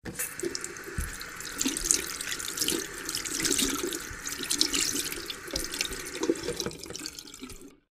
wash.ogg